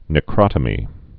(nĭ-krŏtə-mē, nĕ-)